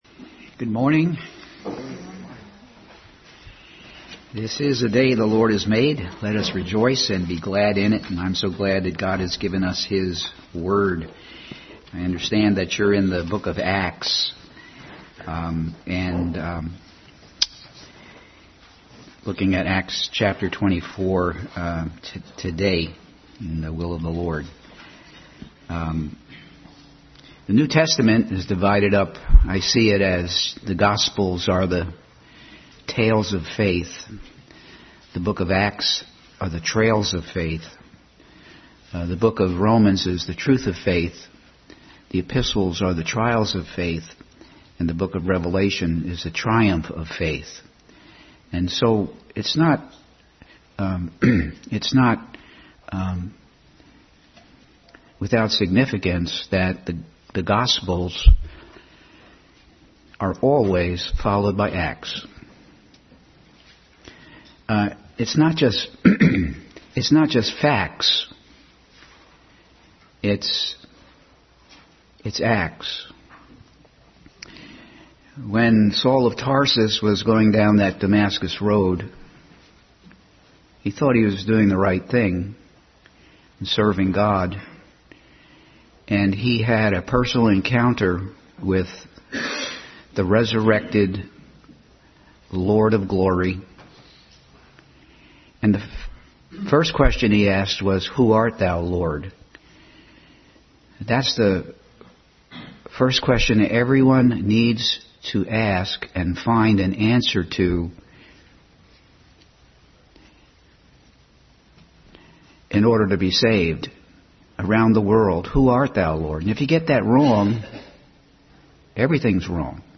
Bible Text: Acts 24:1-27 | Adult SS study in the book of Acts.
Acts 24:1-27 Service Type: Sunday School Bible Text